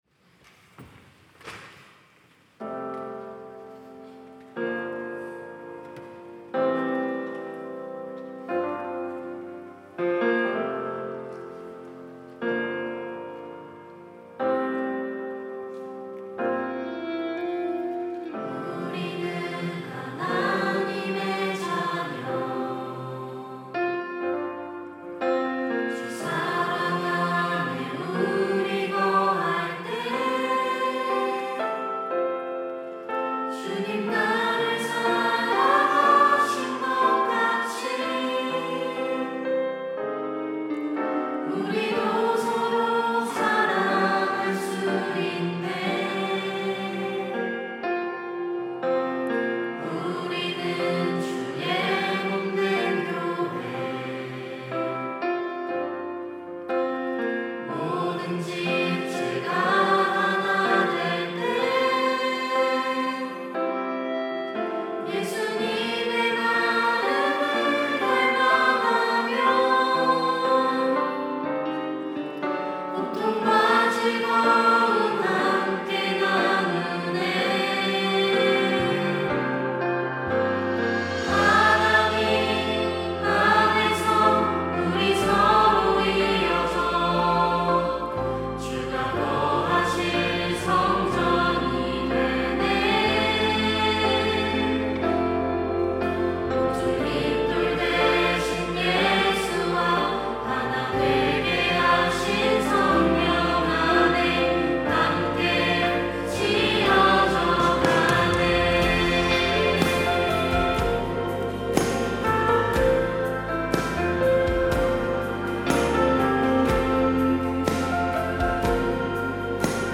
특송과 특주 - 함께 지어져 가네